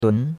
dun2.mp3